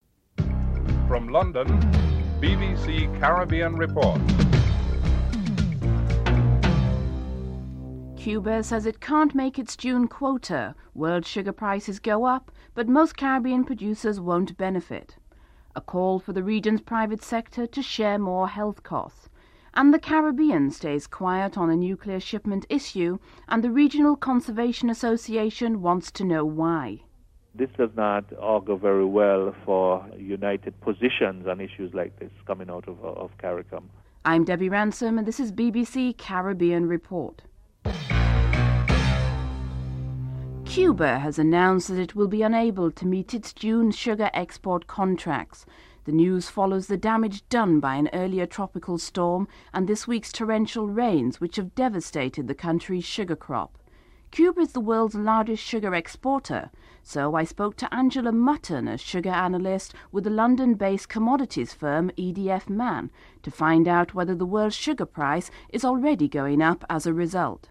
1. Headlines (00:00-00:49)